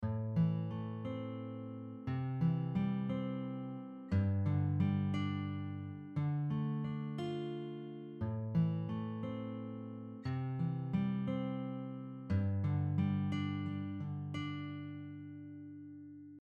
If you were able to figure out the rhythm of the audio clip which happens to be in a 6/8 time signature, the notation would look something like this.